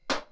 HitMetal2.wav